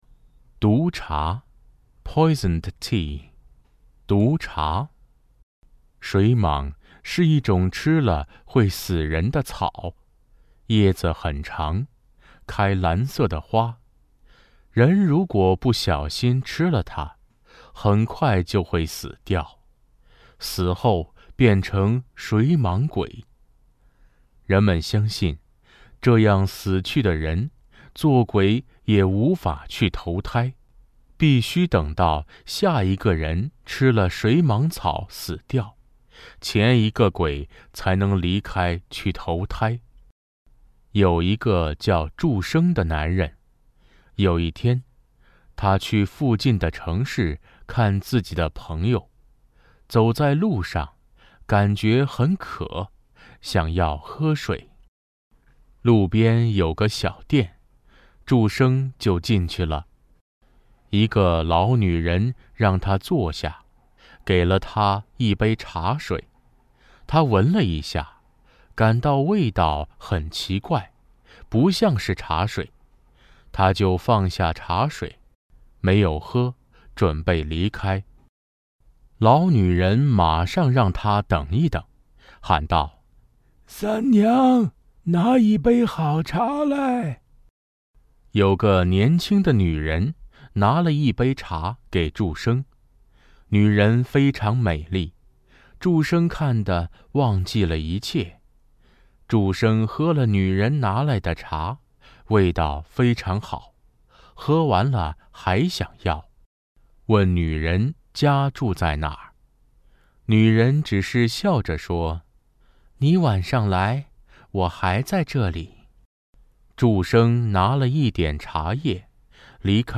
LTRC-4-audiobook-96kbps.mp3